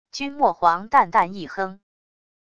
君墨皇淡淡一哼wav音频